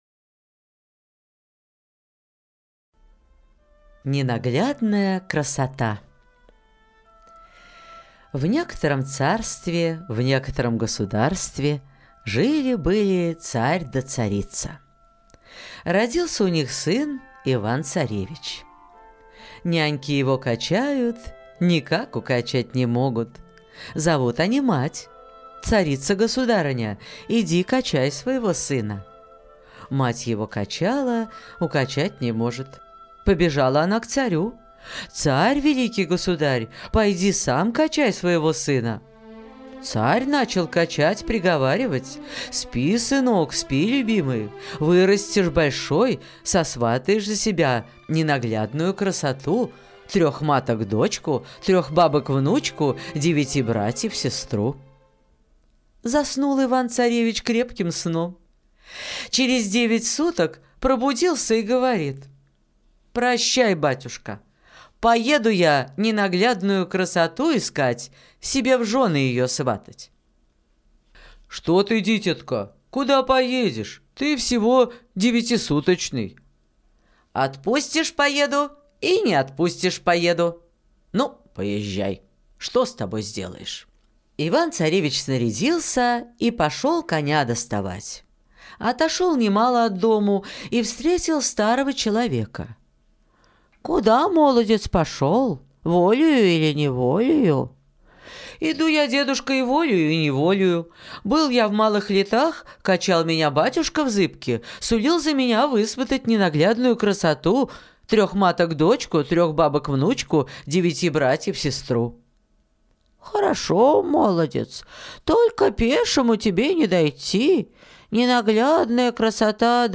Ненаглядная красота - русская аудиосказка - слушать онлайн